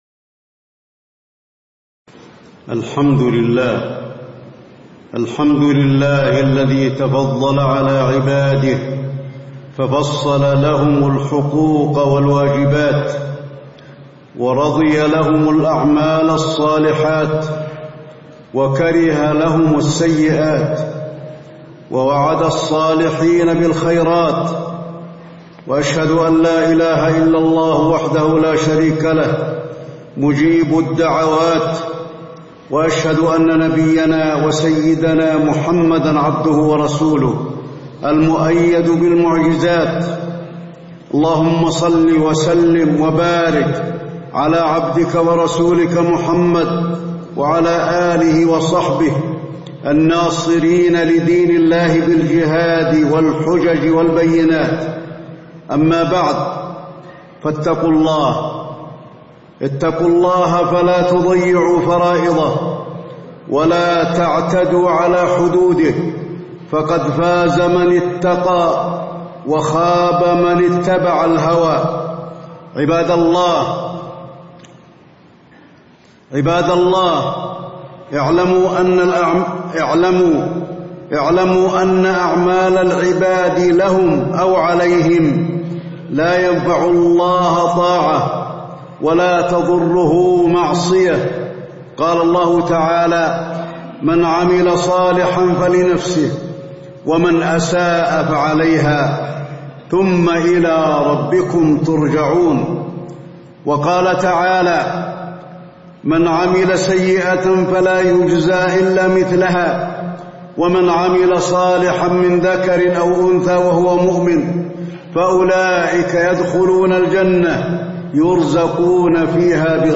تاريخ النشر ٢ جمادى الآخرة ١٤٣٧ هـ المكان: المسجد النبوي الشيخ: فضيلة الشيخ د. علي بن عبدالرحمن الحذيفي فضيلة الشيخ د. علي بن عبدالرحمن الحذيفي الحقوق الواجبة على العباد